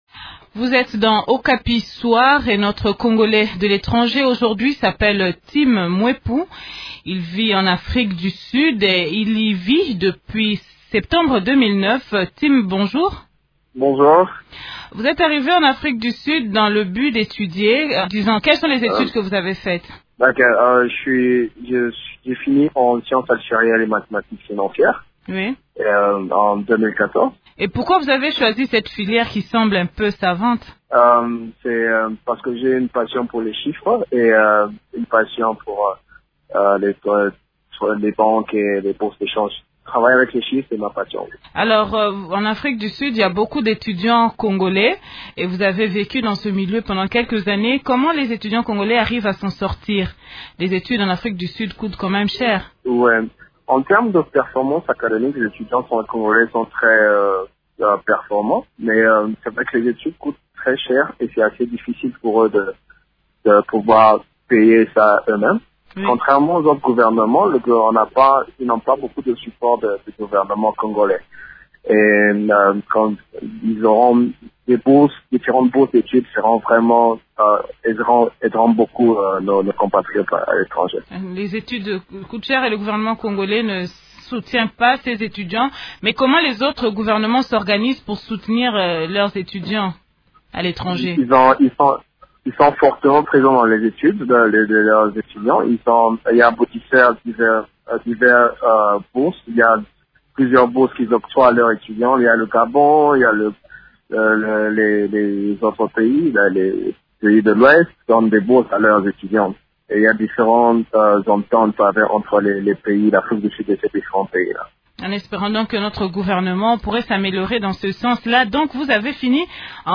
son témoignage dans cette entrevue